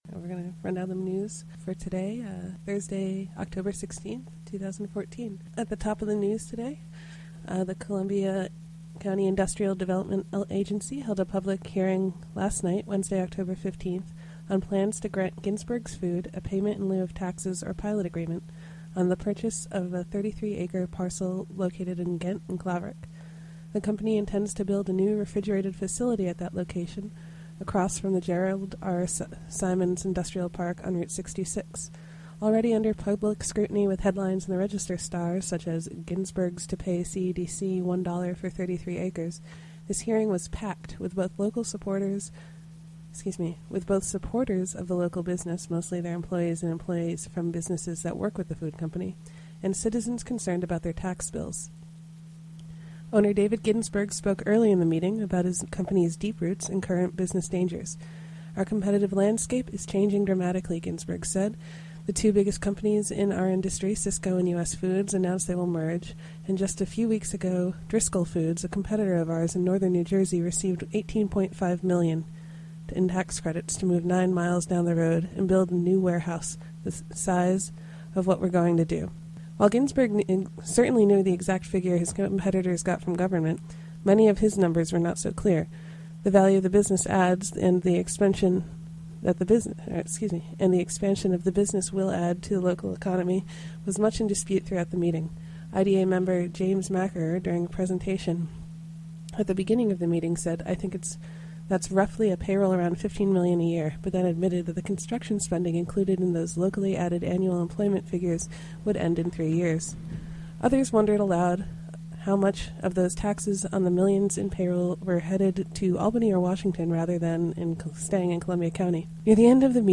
Special hour-long edition featuring recordings from the Columbia County Industrial Development Agency’s public hearing on the Ginsberg’s PILOT proposal. The hearing was held Wed., Oct. 15, 2014 at the A.B. Shaw Firehouse in Claverack. The hearing was conducted for public comment on the tax breaks and incentives in the proposed PILOT (Payment in Lieu of Taxes) agreement for the Ginsberg’s expansion project.